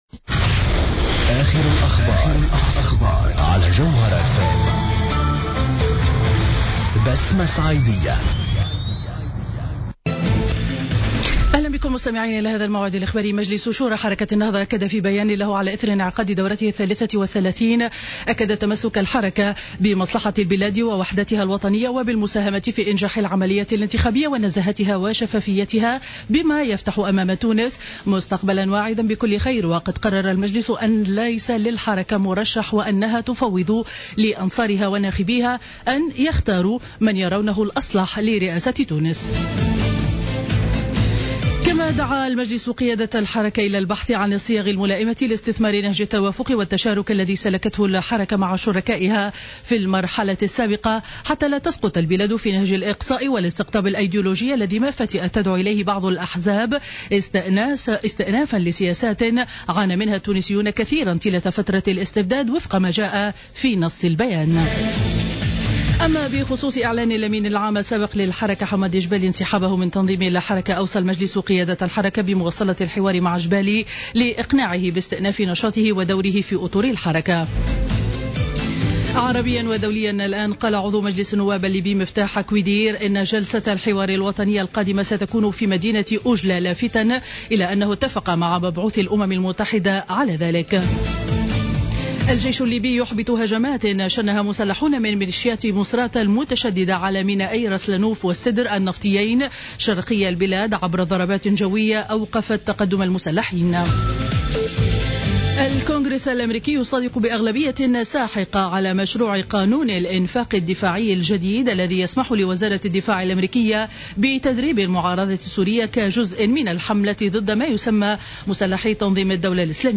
نشرة أخبار السابعة صباحا ليوم الأحد 14-12-14